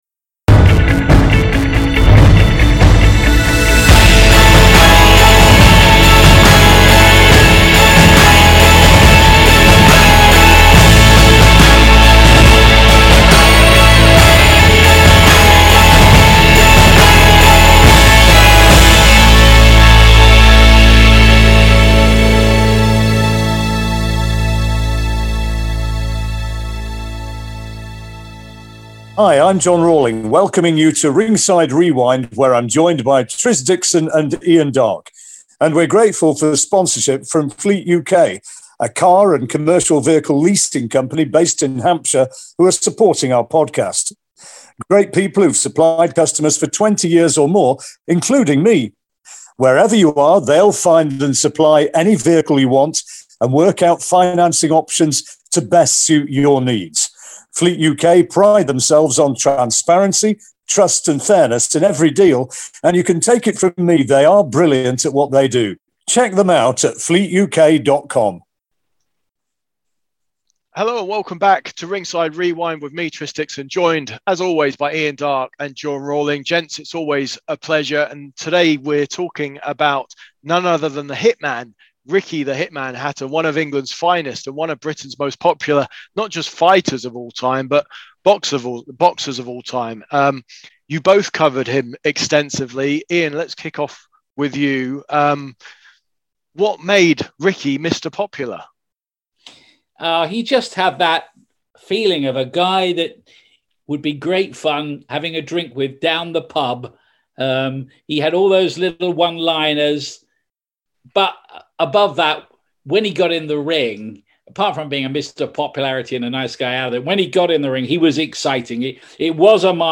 Broadcasting legends Ian Darke and John Rawling reflect on the life and career of Ricky Hatton, painting an intimate and humorous portrait of 'The Hitman' through their own experiences working with a British boxing icon.